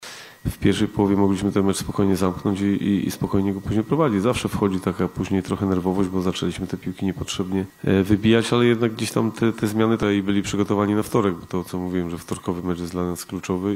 – w taki sposób grę swoich podopiecznych podsumował trener Reprezentacji Polski- Michał Probierz